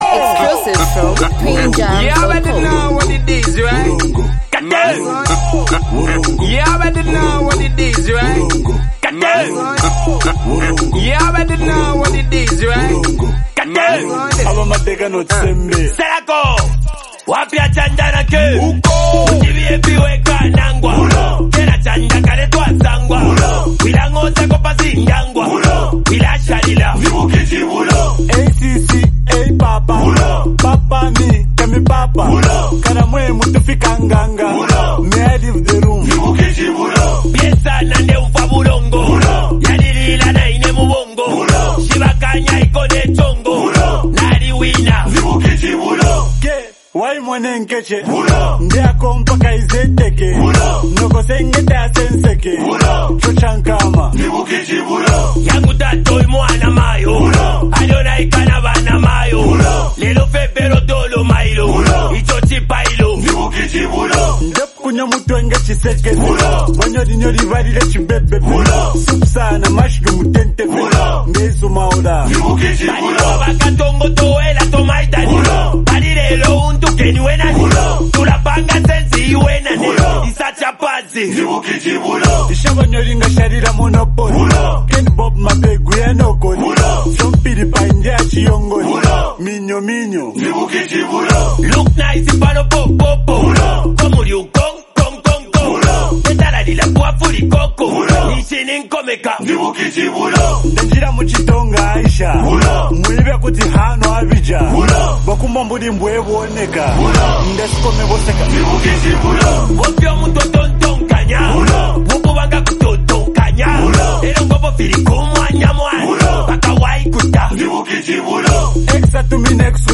Highly multi talented act and super creative duo